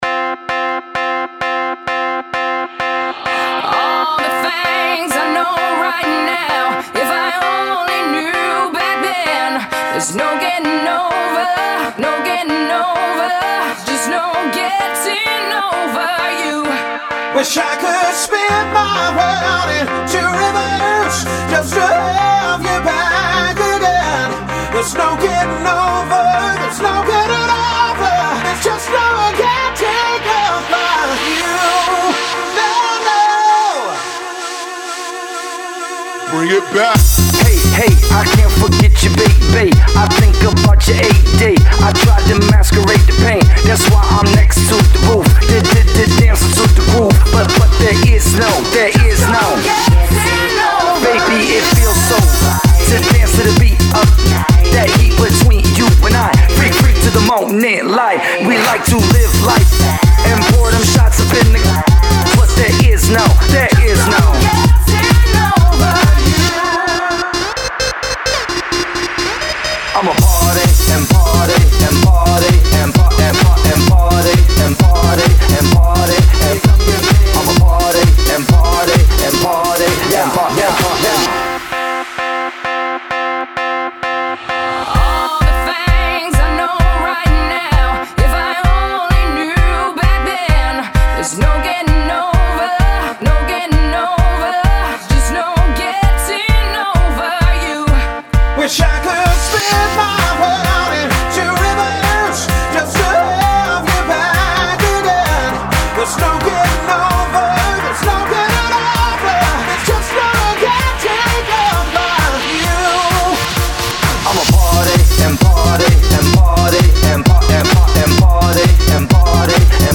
ჟანრი: R&B,Electro,Hause,Pop